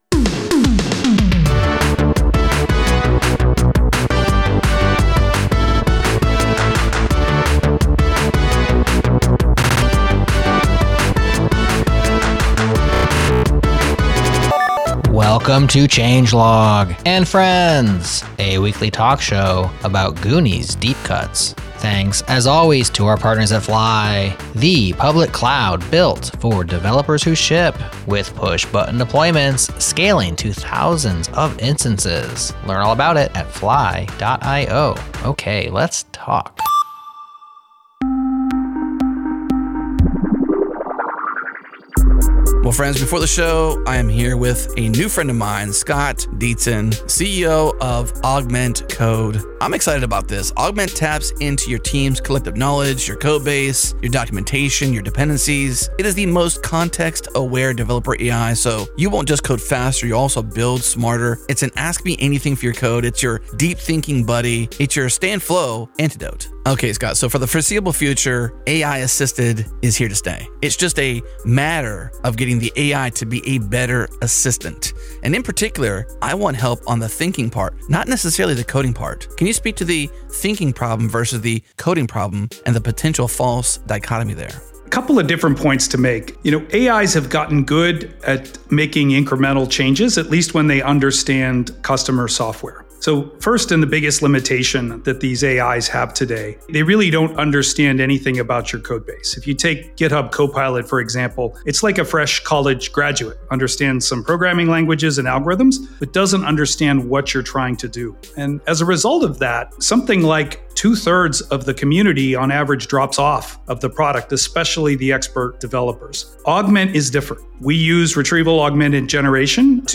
Welcome to Changelog and friends, a weekly talk show about Goonies deep cuts.